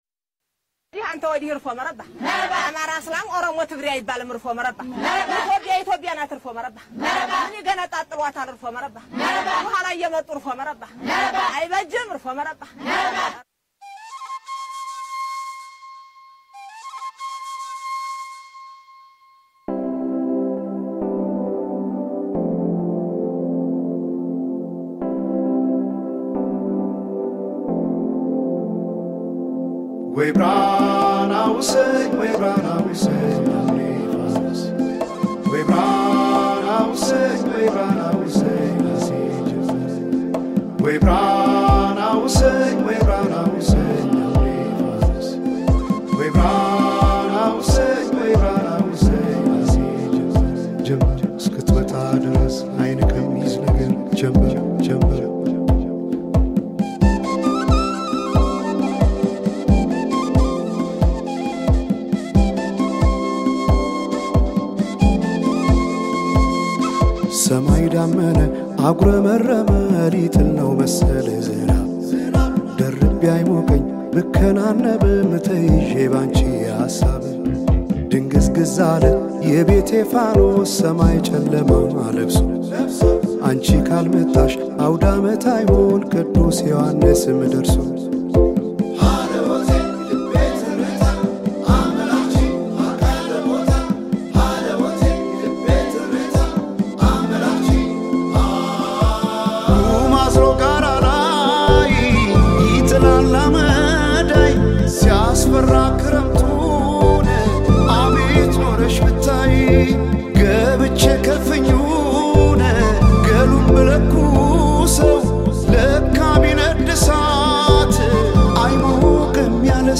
It delivers a sound that stays consistent throughout.